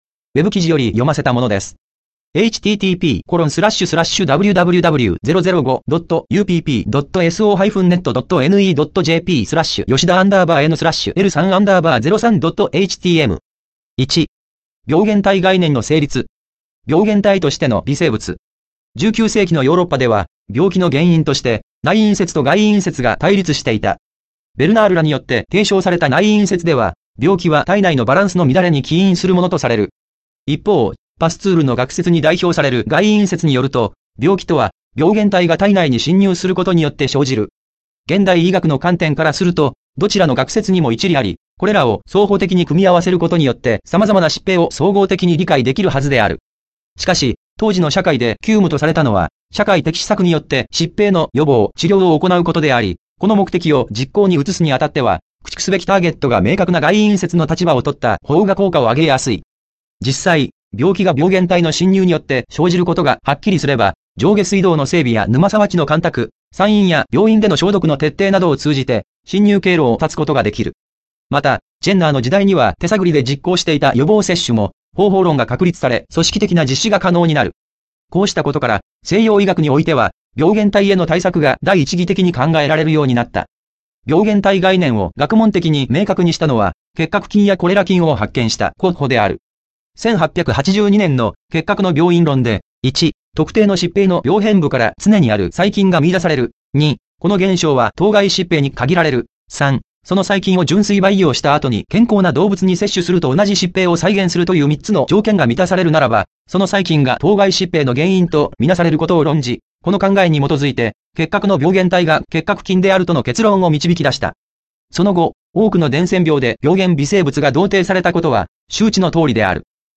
微生物（ウイルス、細菌）病源説はヤメロや 音声読み上げ クリック ↑ 下もクリック ↓